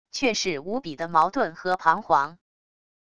却是无比的矛盾和彷徨wav音频生成系统WAV Audio Player